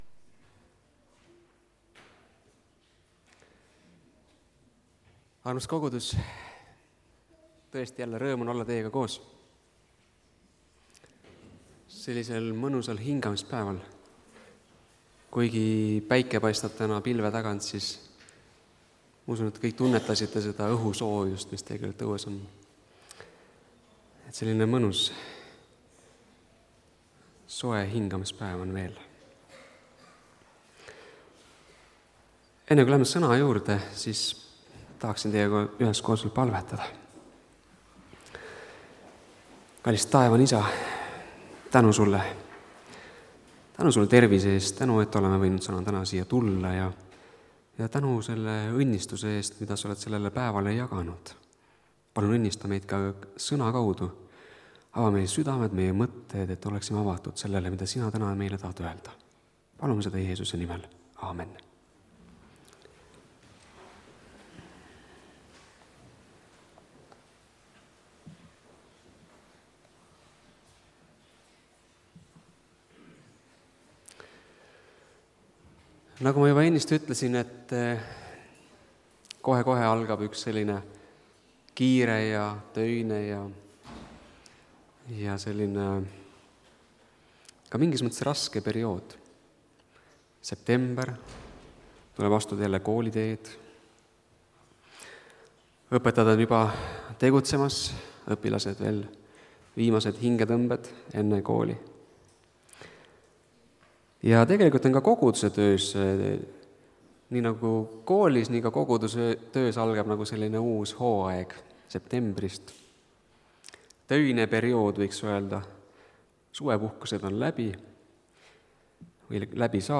Kuulata Jumala Vaimu häält (Tallinnas)
Jutlused